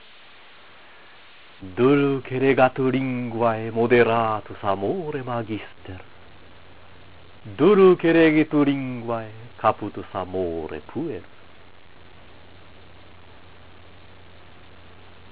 朗読７-８行